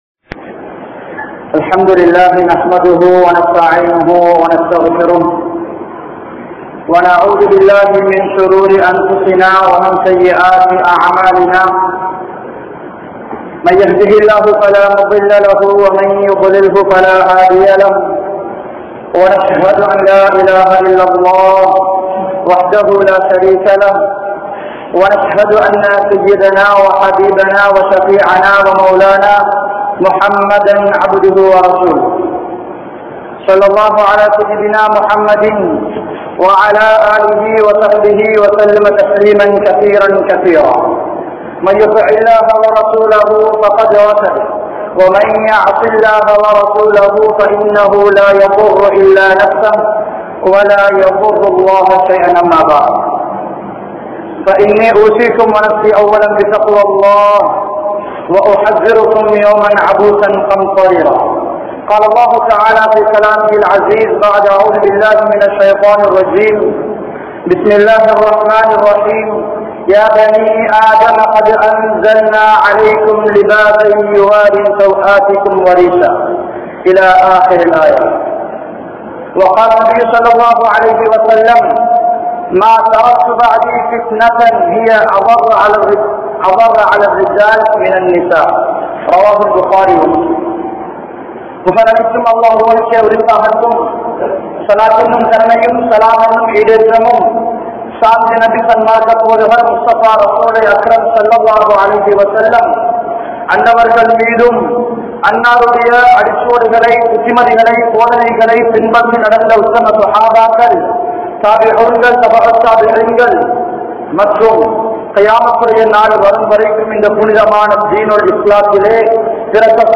Naveena Abaayakkalaal Seeralium Samooham (நவீன அபாயாக்களால் சீரழியும் சமூகம்) | Audio Bayans | All Ceylon Muslim Youth Community | Addalaichenai
Gongawela Jumua Masjidh